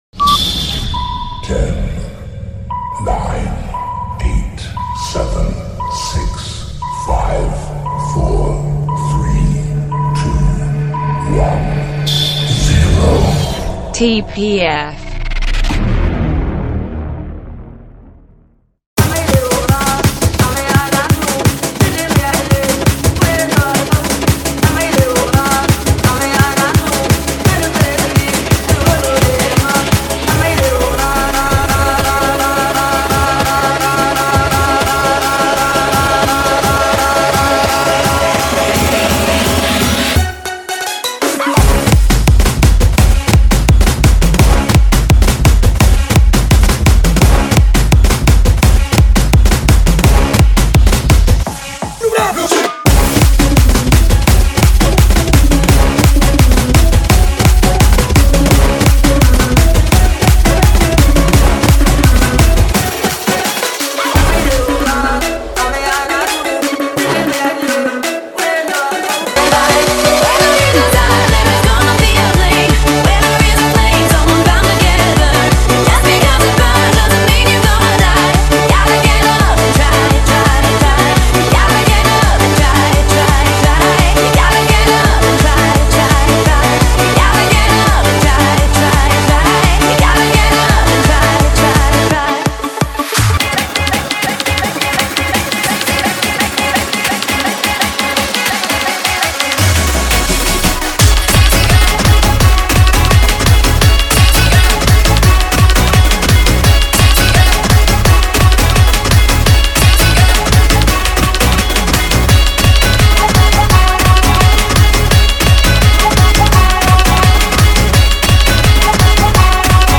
(32 count phrased) 160 BPM
dance, cardio, aerobics, Fitness…
Tempo:      160 BPM